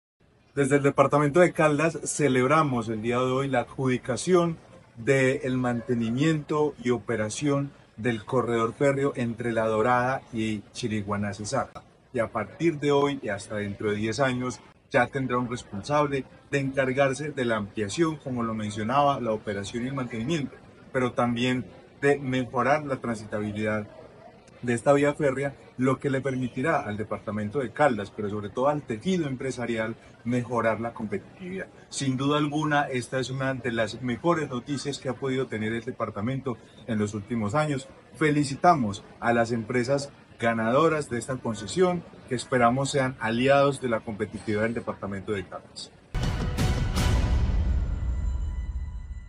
Carlos Anderson García, secretario de Planeación de Caldas.